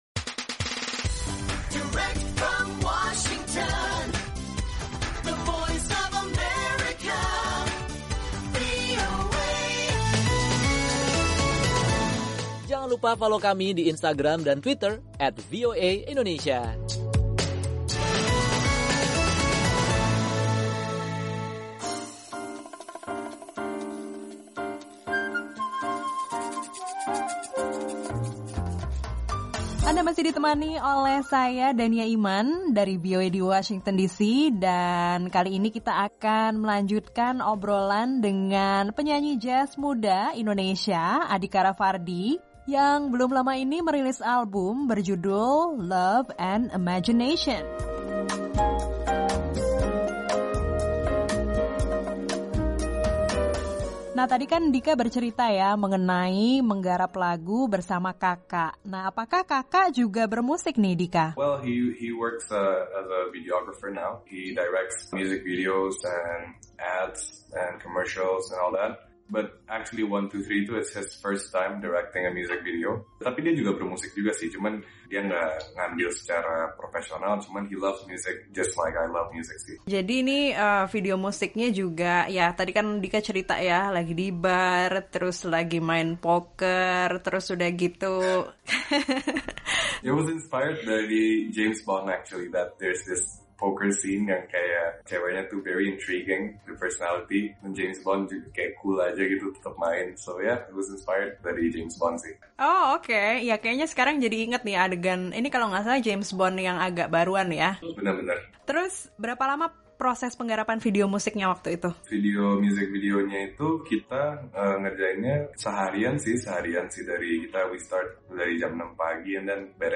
Simak kelanjutan obrolan bersama penyanyi jazz muda